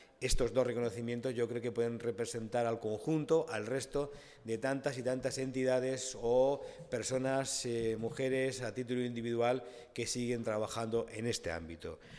Pedro Antonio Ruiz Santos, delegado de la Junta de Comunidades en Albacete.